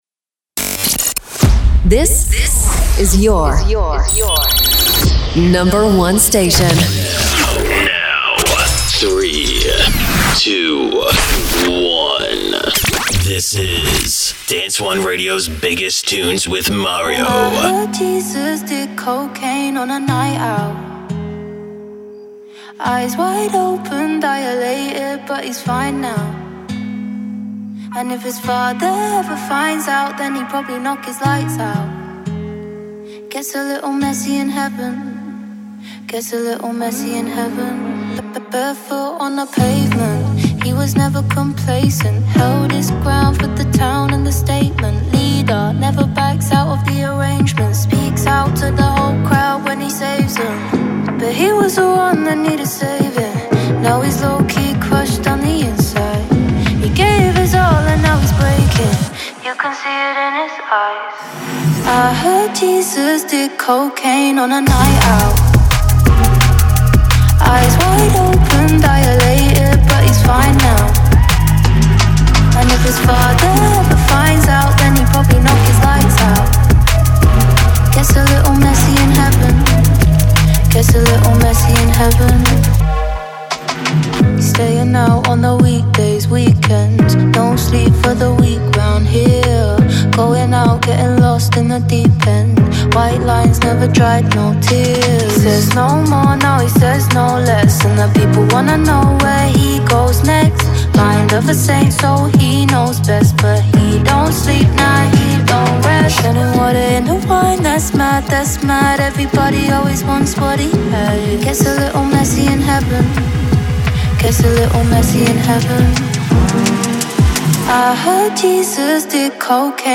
Dance anthems that rule the dance and electronic scene